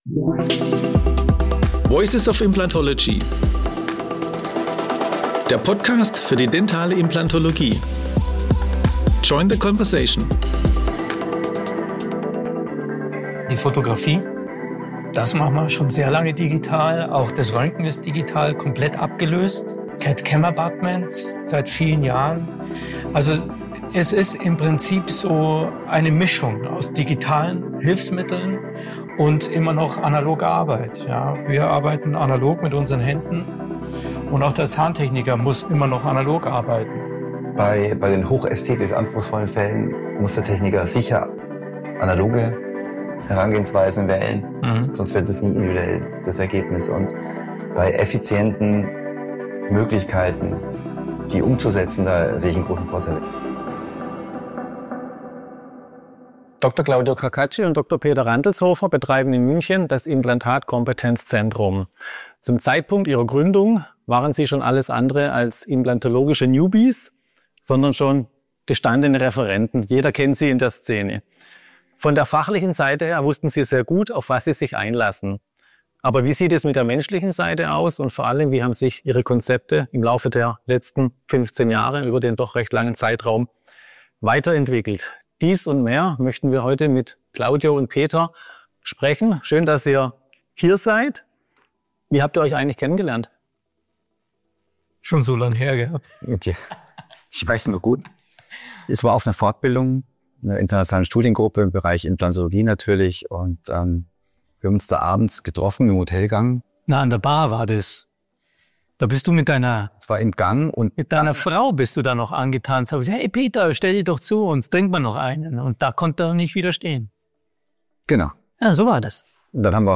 Und welche digitalen Tools haben sich bewährt? Diese Fragen und ein Exkurs zu den Konzepten im zahnlosen Kiefer und bei älteren Patienten waren die Themen im VOI Interview.